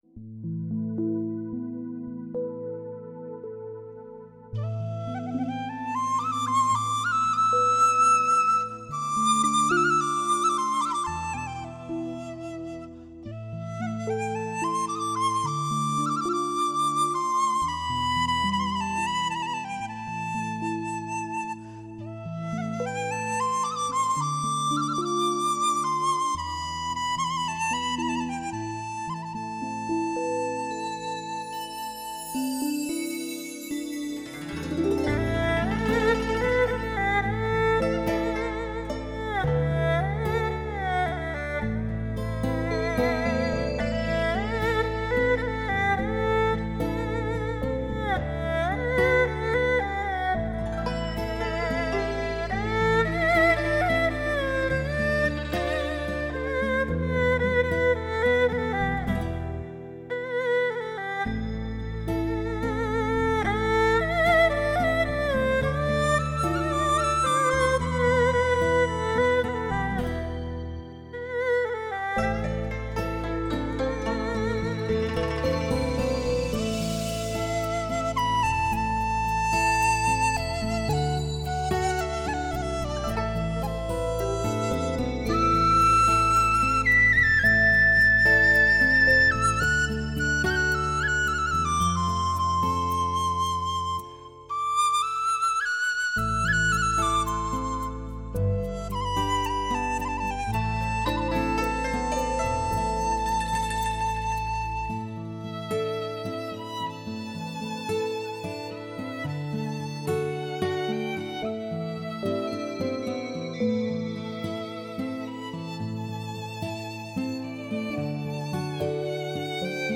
藏笛悠长，
一首轻柔漫妙的曲子，茶的清香与优雅旋律相融合